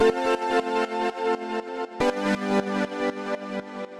Index of /musicradar/sidechained-samples/120bpm
GnS_Pad-dbx1:8_120-A.wav